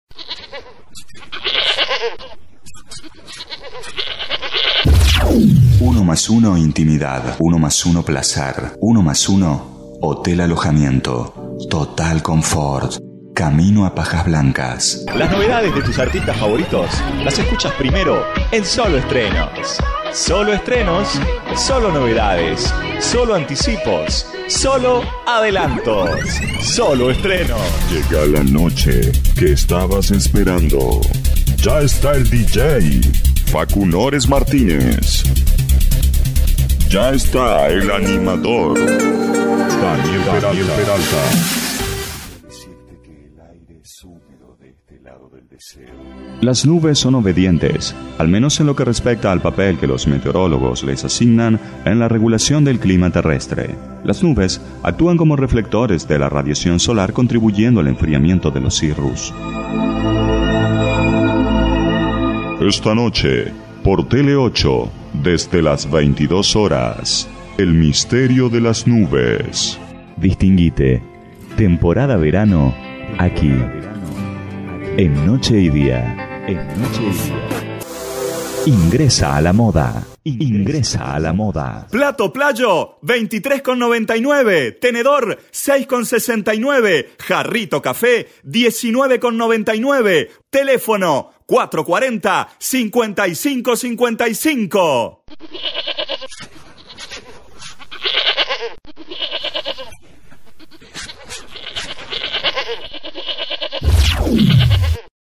Sprecher | World Wide Voices
Locución comercial, informativo, doblaje.